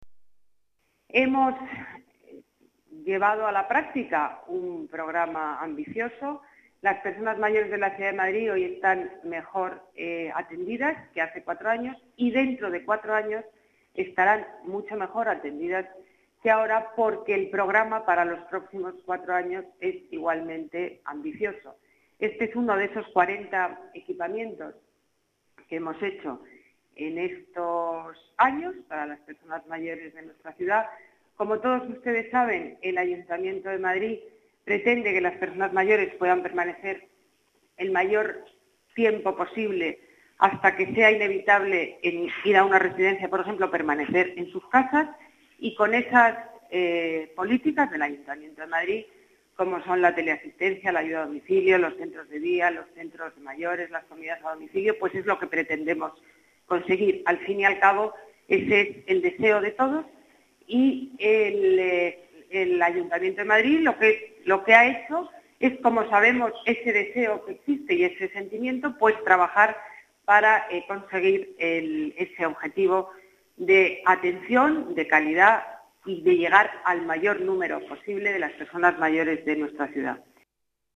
Nueva ventana:Declaraciones de Ana Botella en la inauguración de este nuevo equipamiento